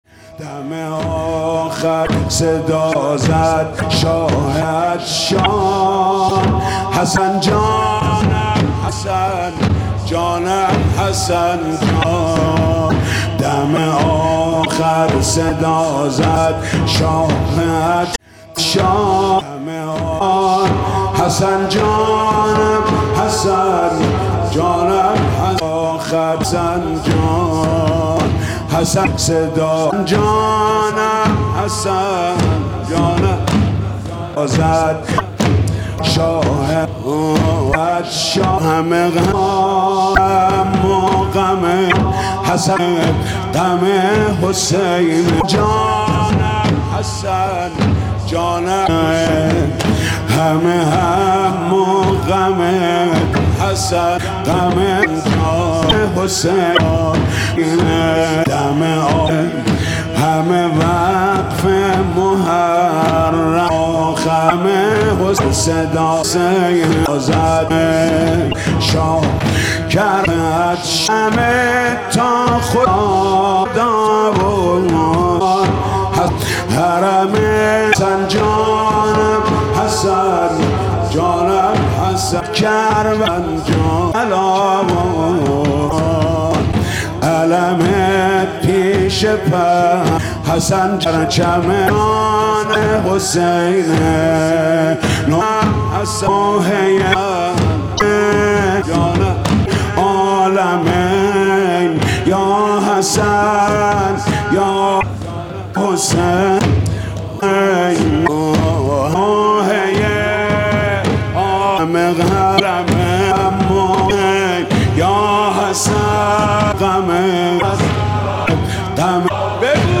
دم آخر صدا زد شاه عطشان مداحی جدید حاج محمود کریمی شب ششم محرم 1400
شب ششم محرم 1400
دمام زنی